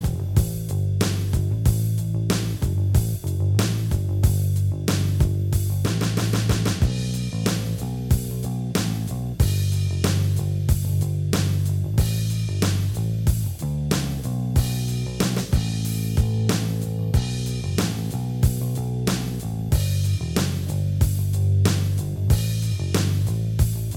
Minus Guitars Rock 3:46 Buy £1.50